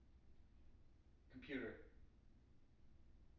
wake-word
tng-computer-248.wav